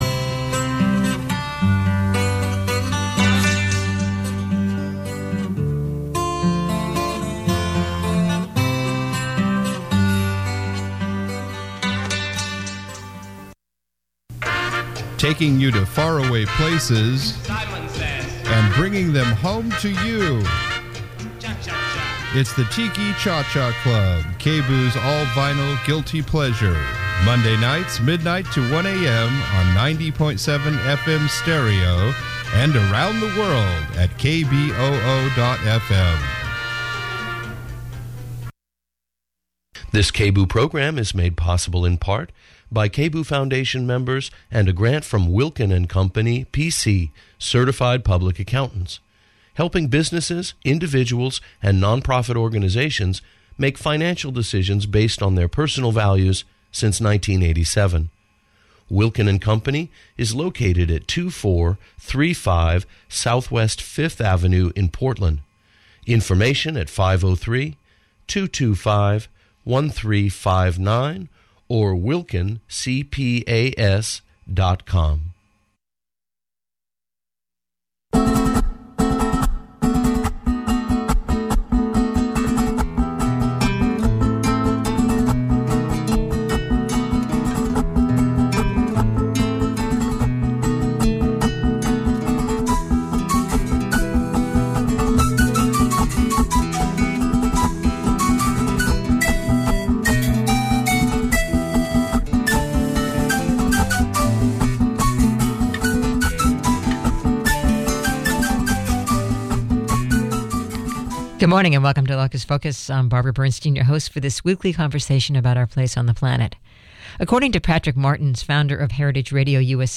Download audio file A roundtable discussion from the Public Interest Environmental Law Conference held at the University of Oregon Law School in early March.